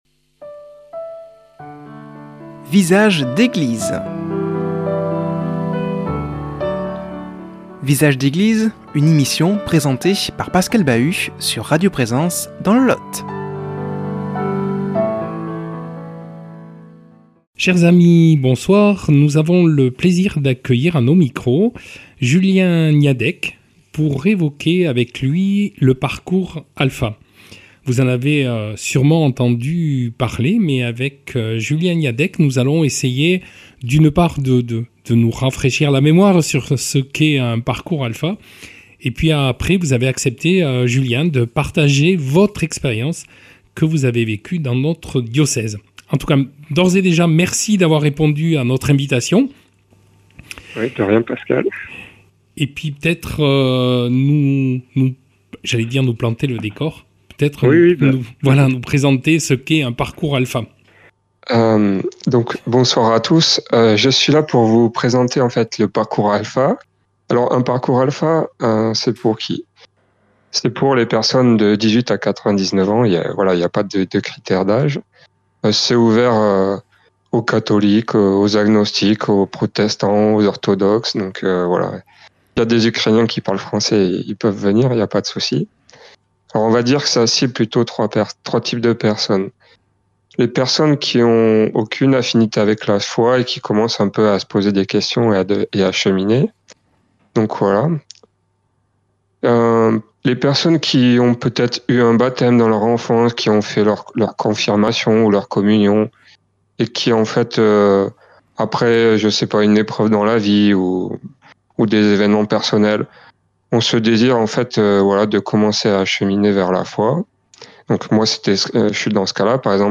reçoit comme invité par téléphone